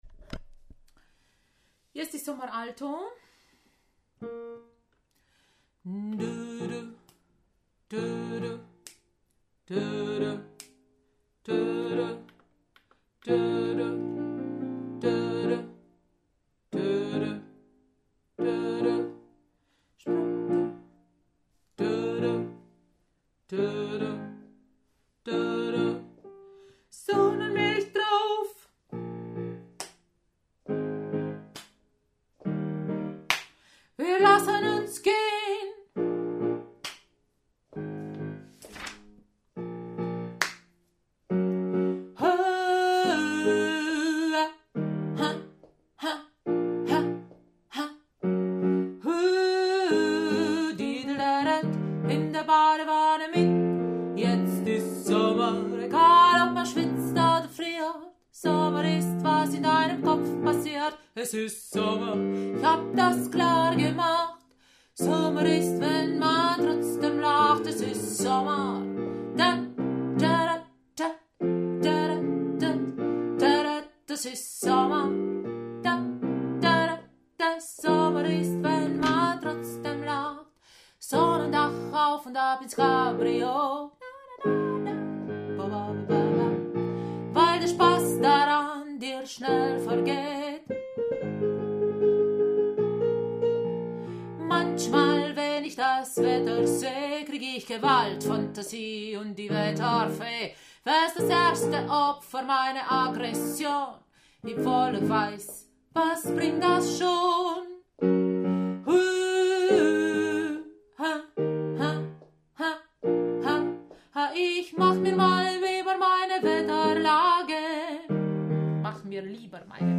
Jetzt-ist-Sommer-Alto.mp3